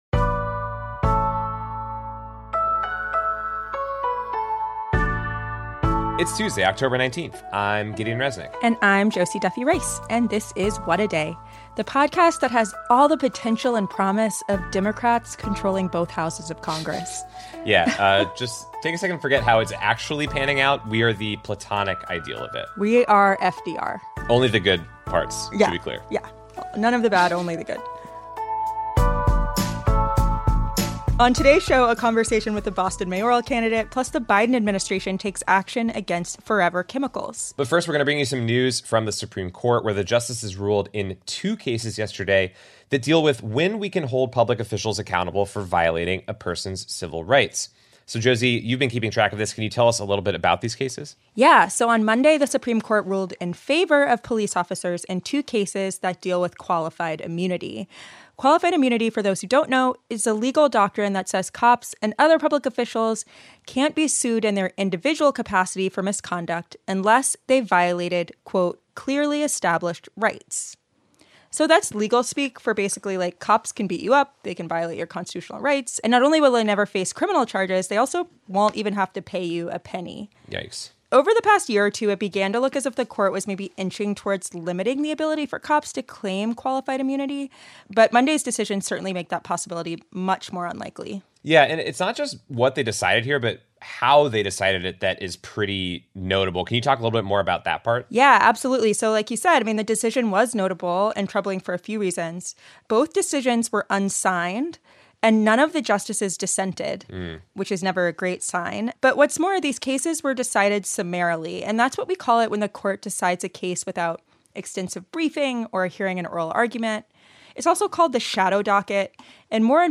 Boston is poised to elect its first woman of color as mayor, and the election is just two weeks away. We talked with candidate Michelle Wu.